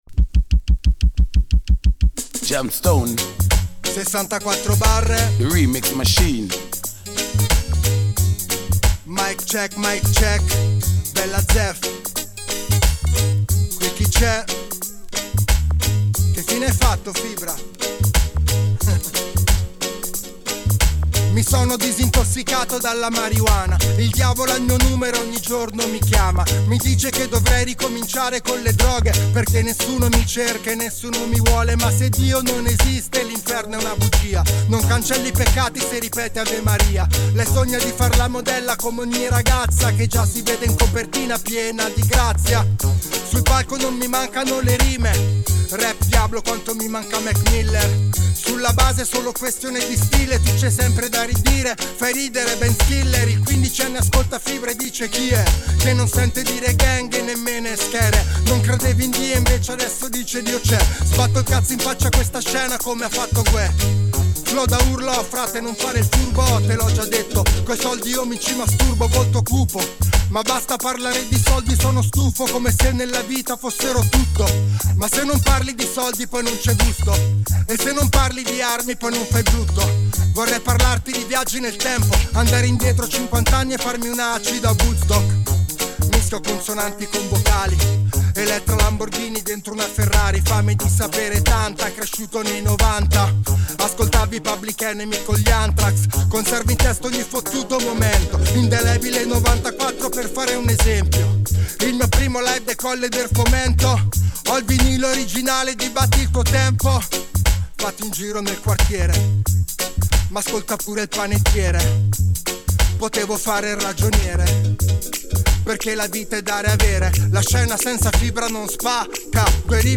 riddim